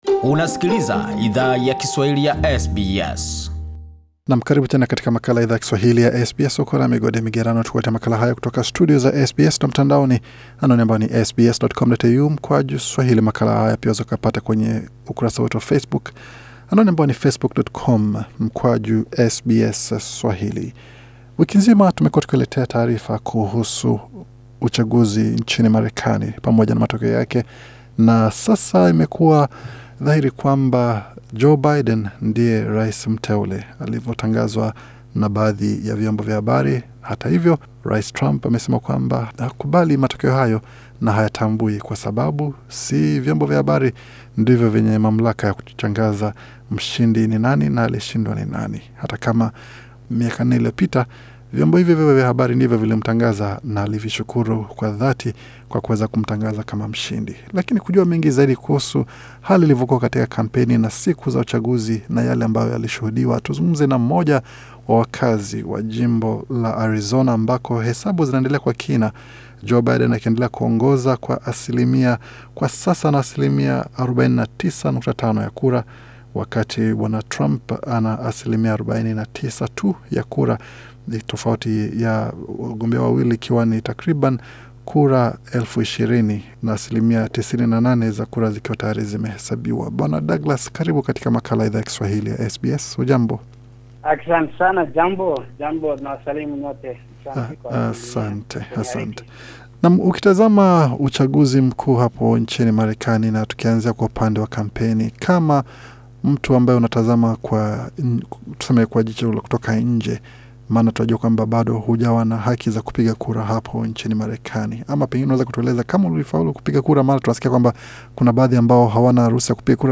Katika mazungumzo maalum na Idhaa ya Kiswahili ya SBS